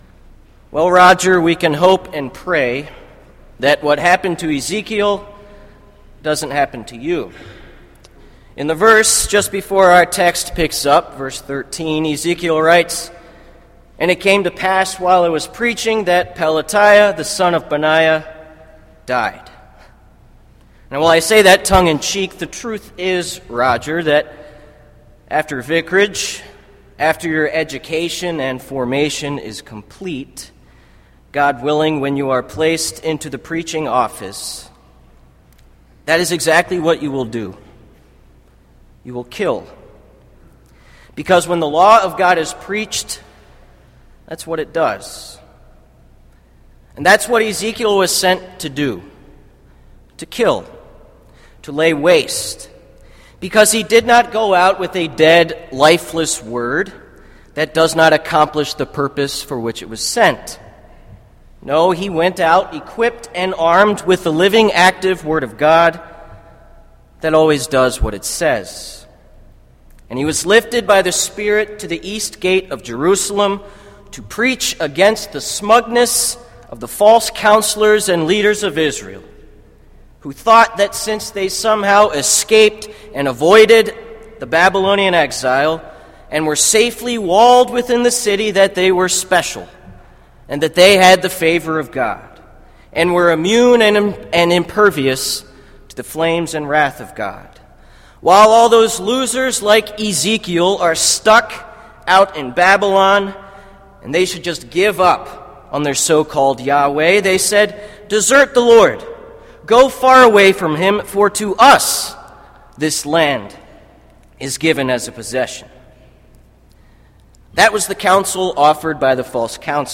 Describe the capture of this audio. Kramer Chapel Sermon - November 14, 2013